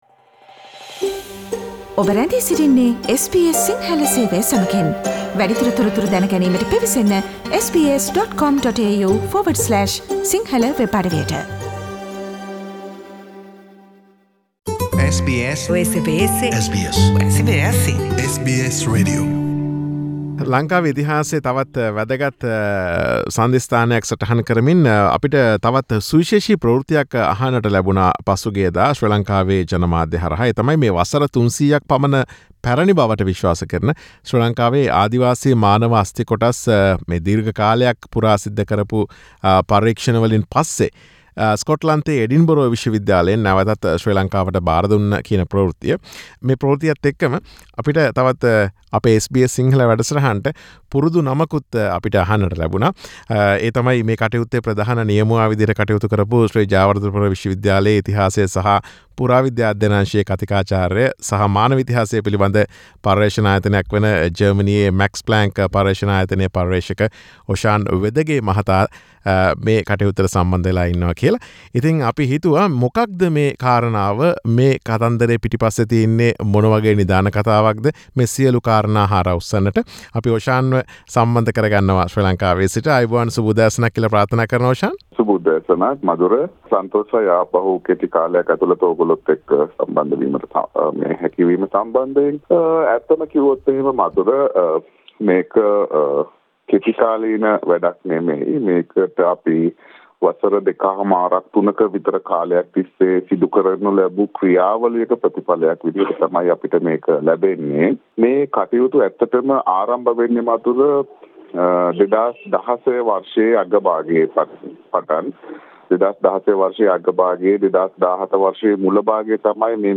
SBS සිංහල සේවය සිදුකළ සාකච්ඡාව